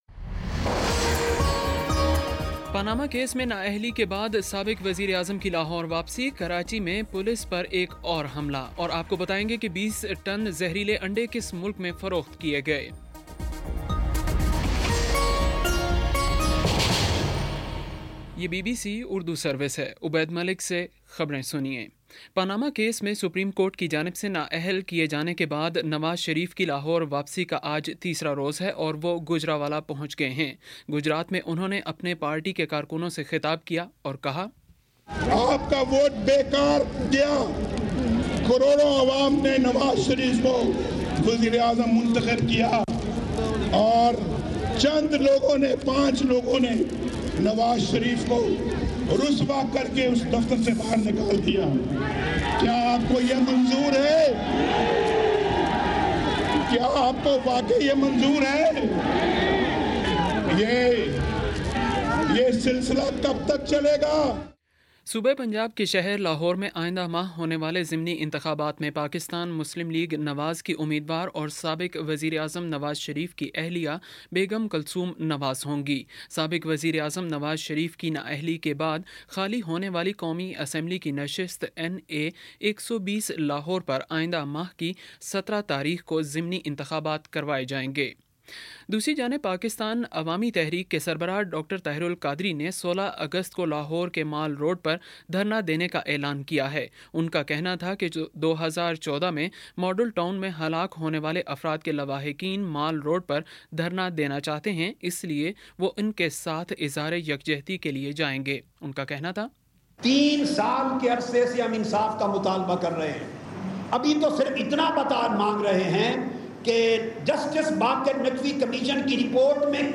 اگست 11 : شام چھ بجے کا نیوز بُلیٹن